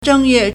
正月 zhēngyuè
zheng1yue4.mp3